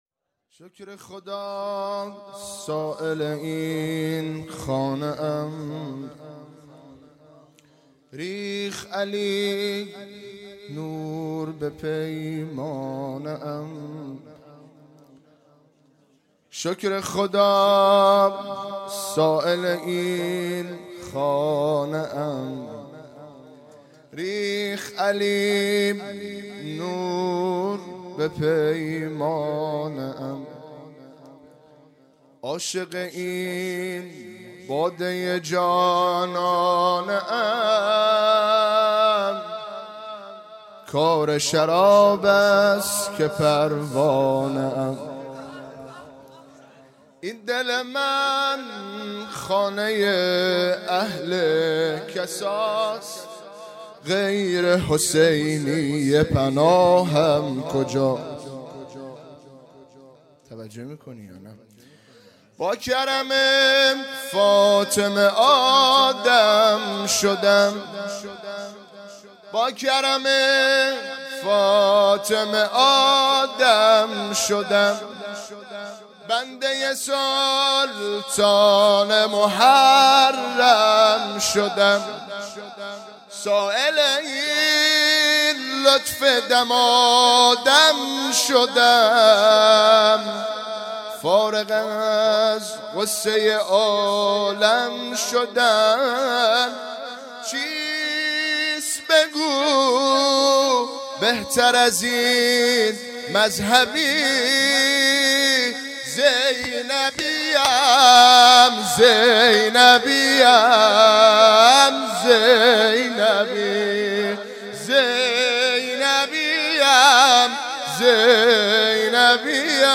مجموعه صوتی مراسم ولادت حضرت زینب سلام الله علیها 97
مدح خوانی